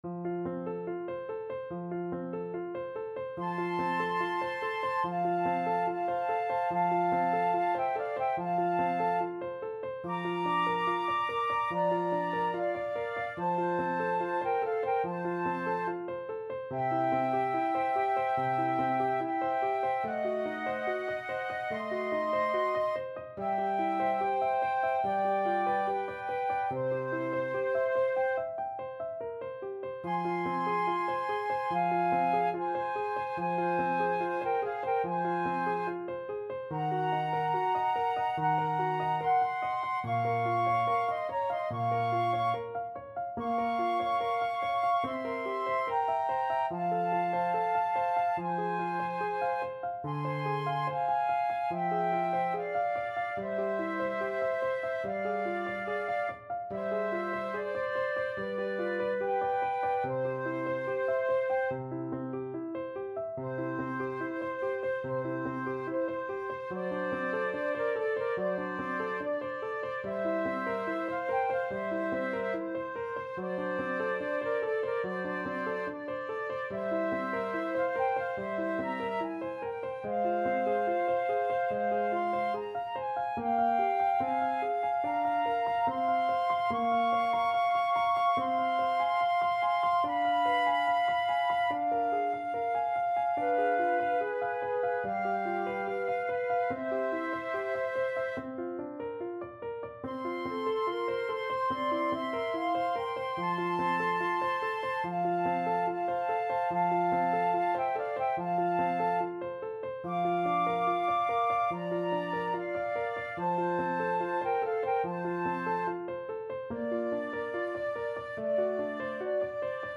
4/4 (View more 4/4 Music)
Andante =72
Flute Duet  (View more Intermediate Flute Duet Music)
Classical (View more Classical Flute Duet Music)